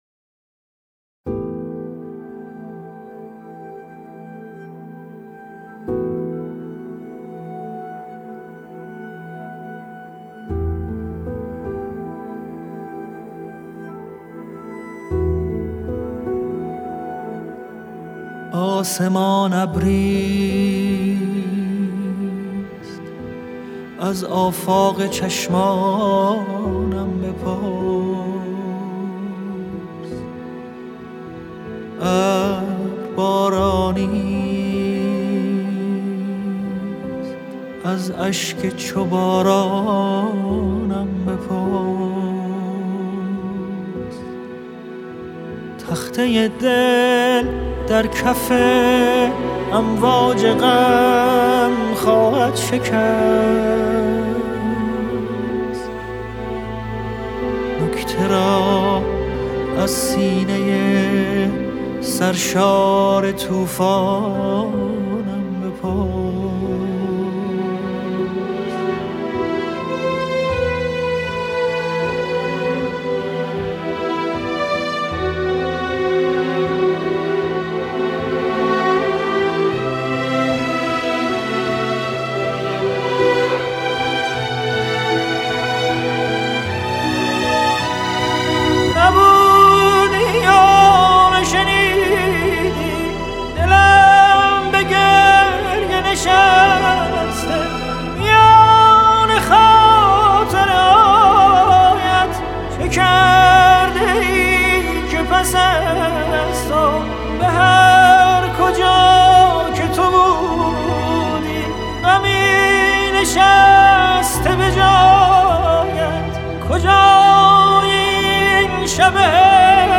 تیتراژ فیلم و سریال ، غمگین